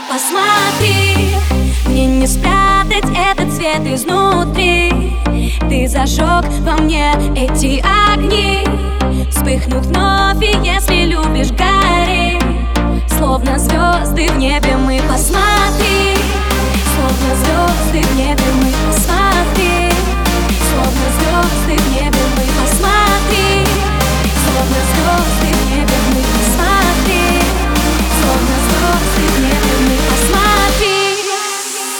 • Качество: 320, Stereo
громкие
женский вокал
зажигательные
Зажигательный, танцевальный remix